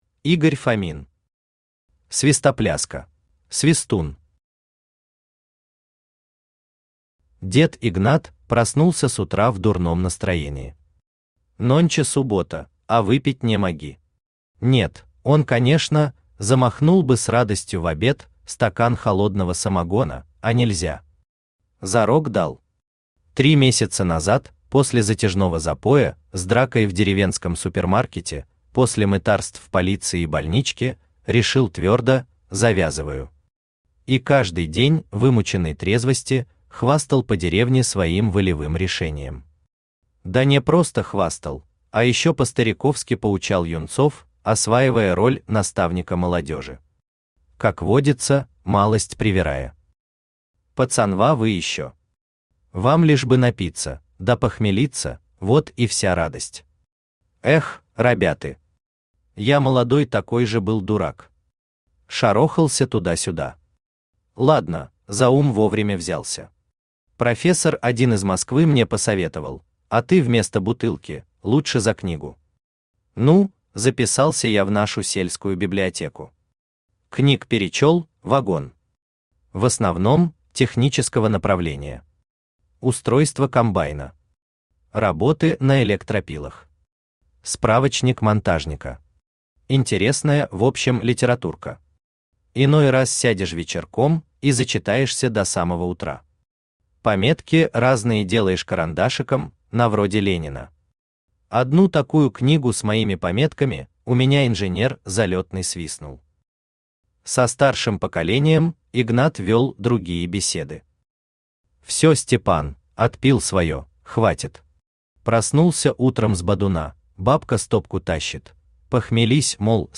Aудиокнига Свистопляска Автор Игорь Фомин Читает аудиокнигу Авточтец ЛитРес.